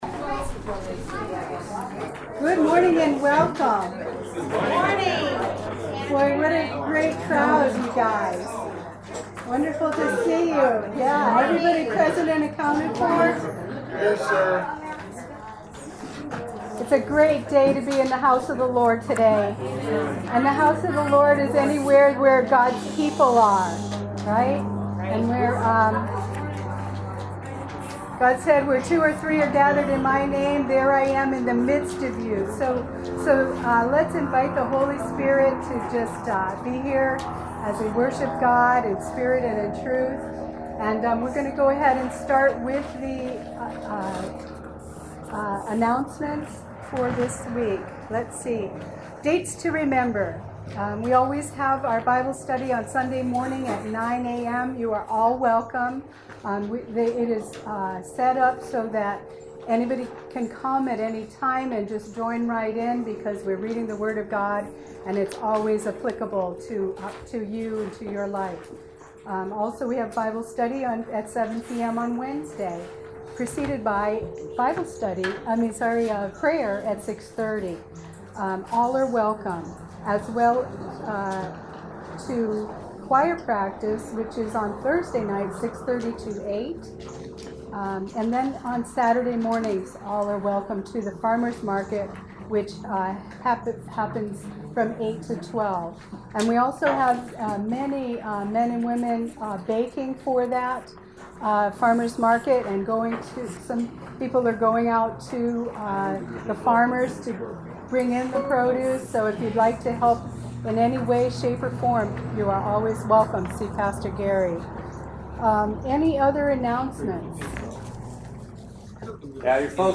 June 26th, 2016 Service Podcast
Welcome to the June 26th, 2016 Service Podcast.